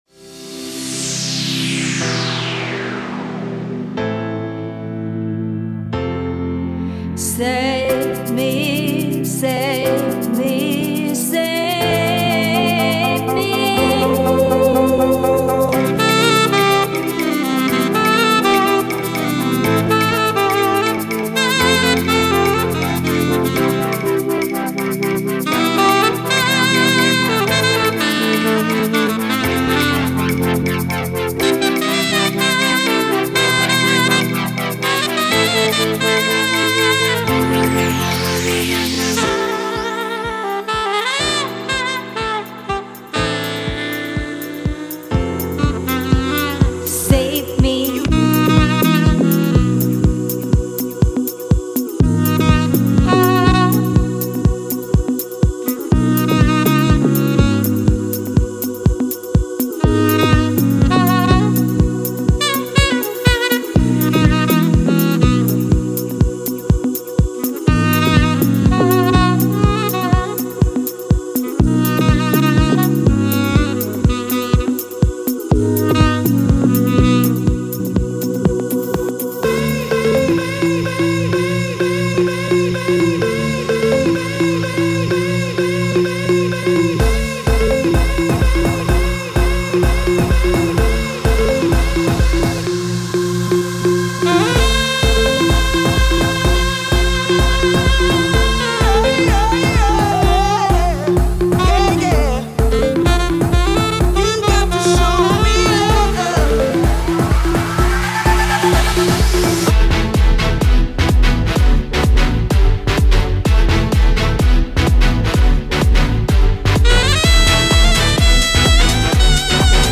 From £975 + travel | Sax and DJ Duo